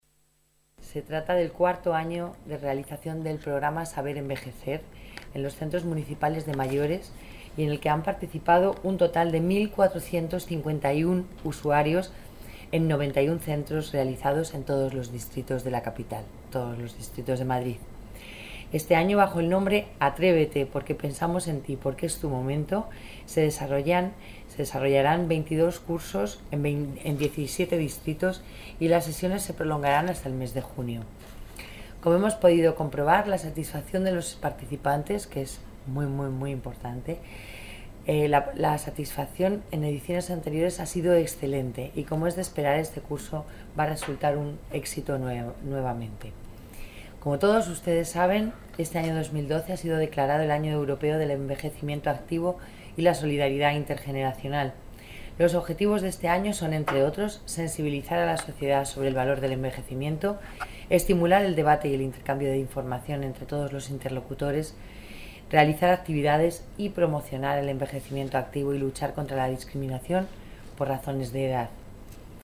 Nueva ventana:Declaraciones de la delegada de Familia y Asuntos Sociales, Lola Navarro